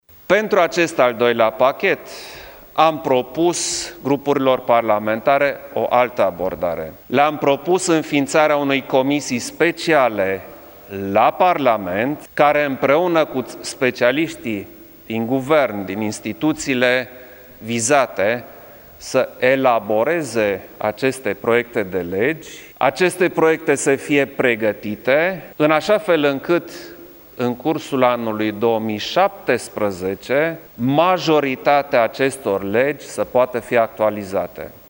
„Am propus o comisie specială la Parlament, care împreună cu specialiști din Guvern și instituțiile vizate să elaboreze proiectele de legi, astfel încât în 2017 majoritatea acestor legi să poată fi actualizate”, a declarat șeful statului: